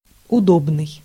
Ääntäminen
US : IPA : [ˈkʌmf.tə.bəl]